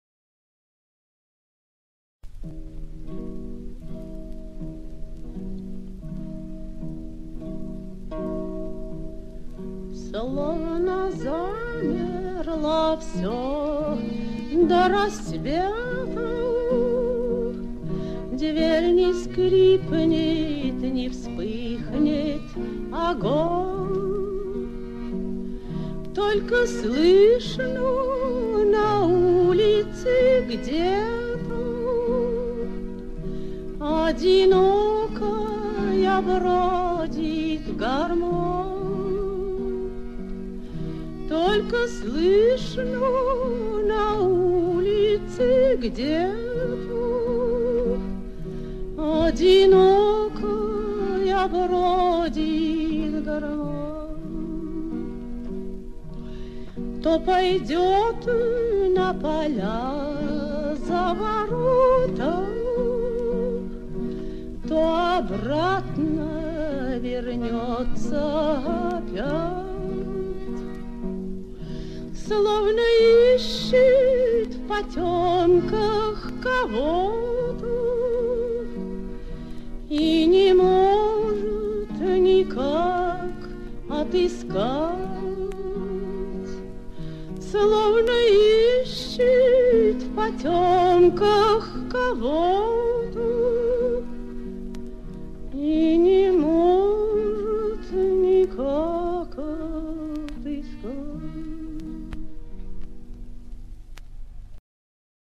Описание: По многим источникам это первое исполнение песни.